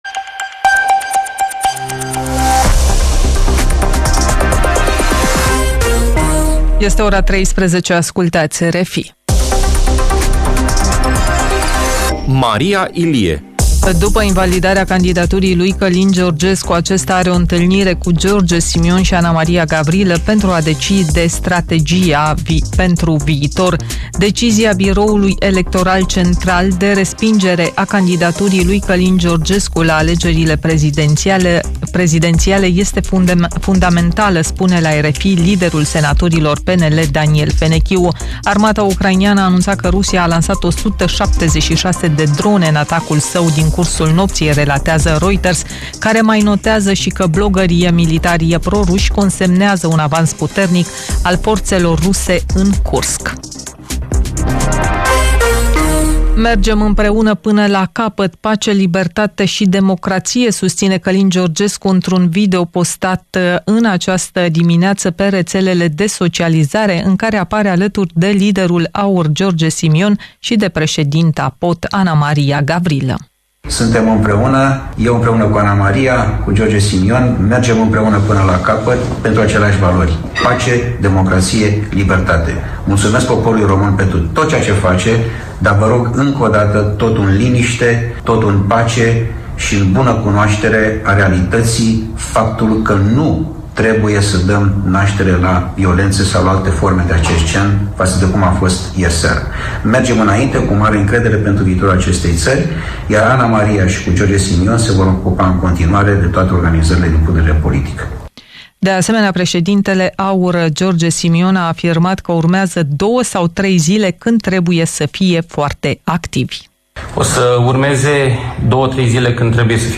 Jurnal de știri